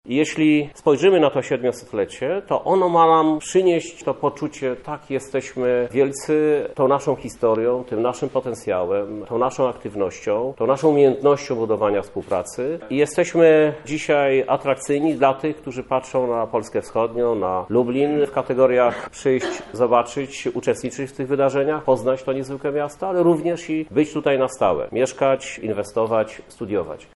Takiej prezentacji Wrocław się nie spodziewał i takiej długo mieć nie będzie, mówi Krzysztof Żuk – Prezydent Miasta Lublin.